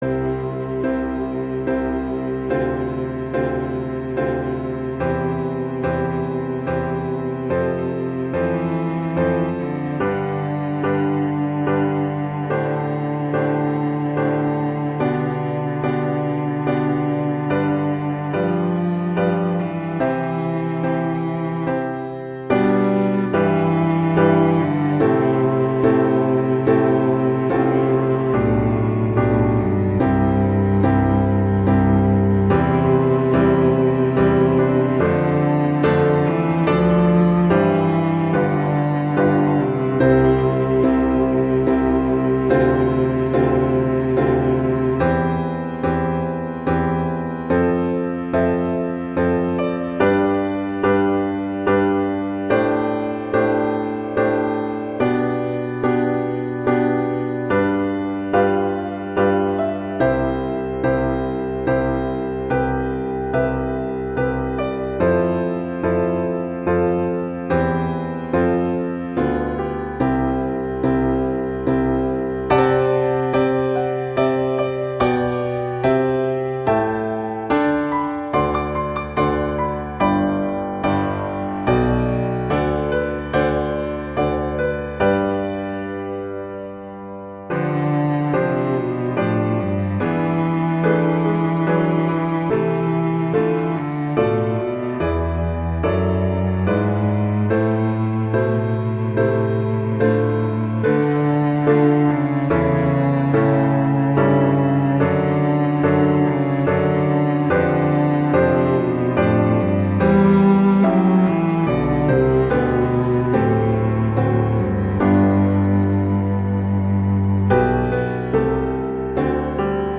classical, easter, wedding, festival, love, children
C major
♩=72 BPM